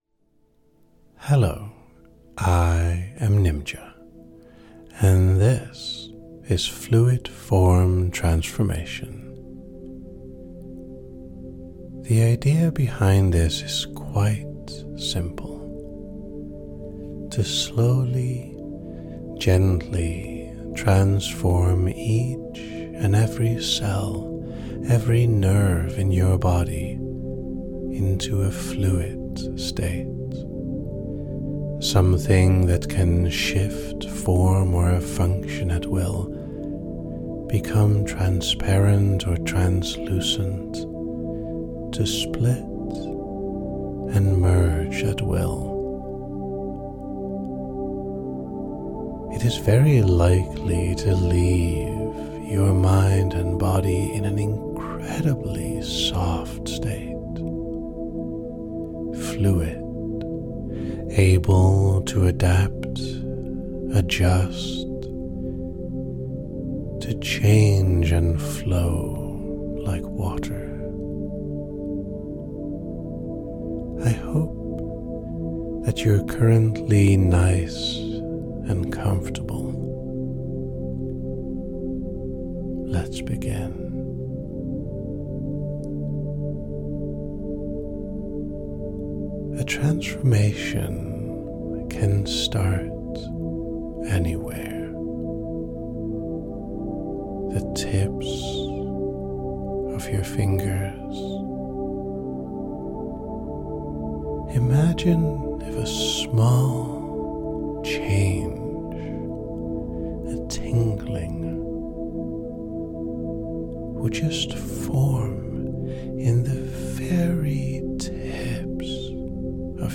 Type Gentle Length 20:37 Categories Induction, Transformation Like it?